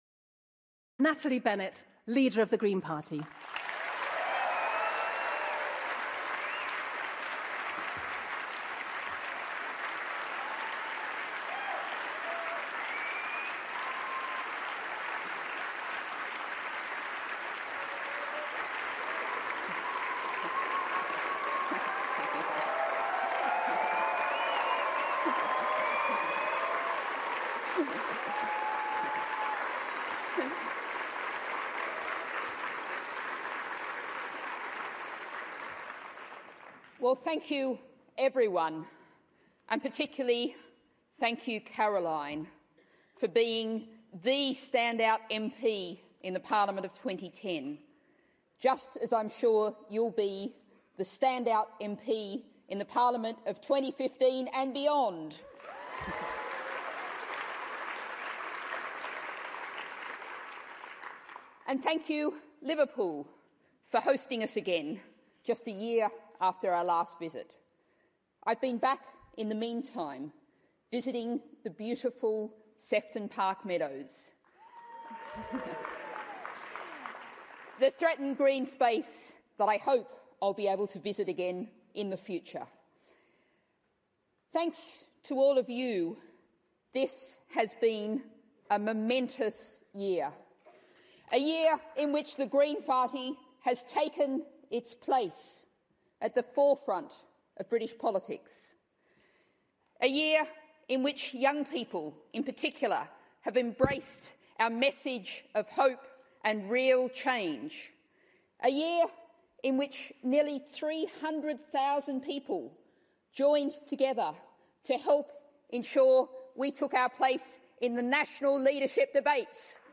Natalie Bennett speaking at the Green Party Conference